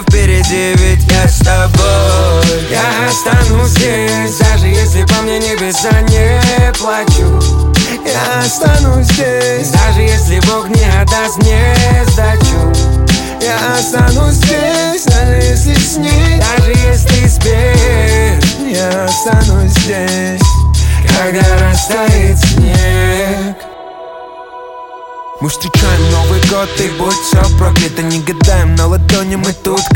Жанр: Русские
# Хип-хоп